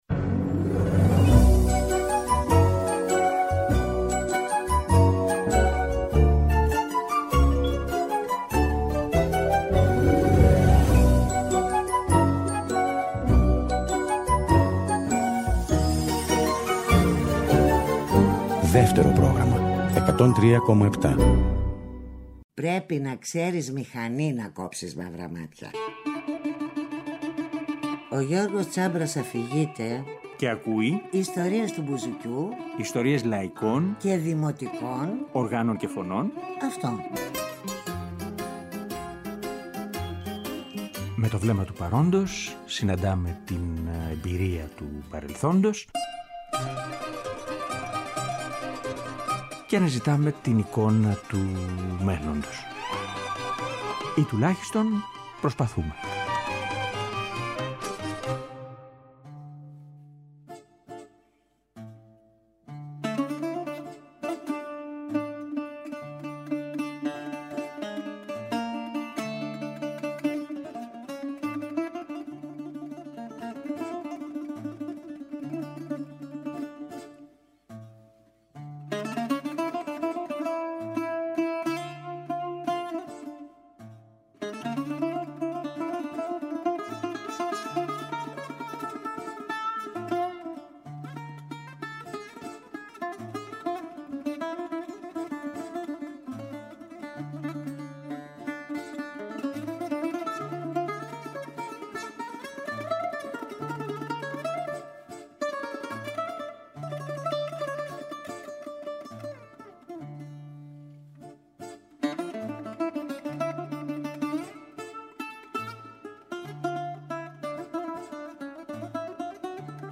Στην εκπομπή μιλάμε για όλο αυτό το ταξίδι, ακούγοντας ηχογραφήσεις που παίζει ή τραγουδά, αλλά και κάποιες δικές του δημιουργίες.